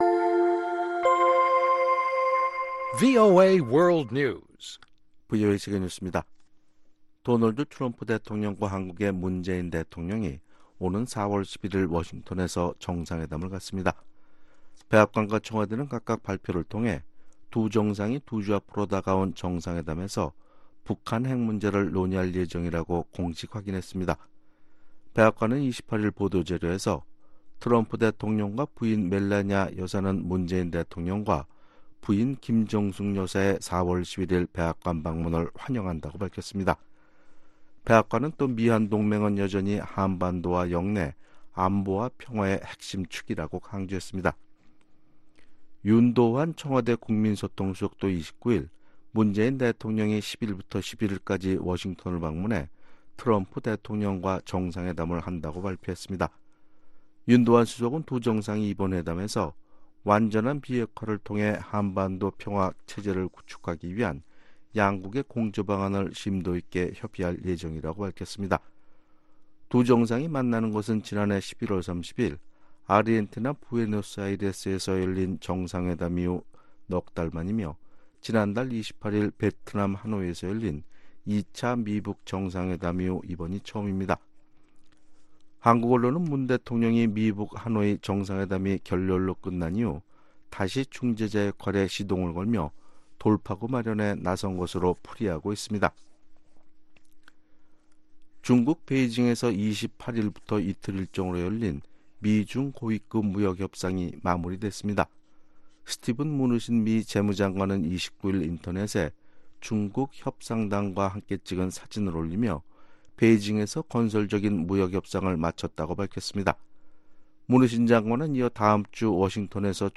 VOA 한국어 아침 뉴스 프로그램 '워싱턴 뉴스 광장' 2019년 3월 30일 방송입니다. 미국 국무부는 북한과의 협상 진전에 대한 기대감을 거듭 강조했습니다. 미국 국무부가 한국 업체가 연루되거나 한반도 인근에서 이뤄지는 제재 위반 행위와 관련해 미국의 대응의지를 재확인했습니다.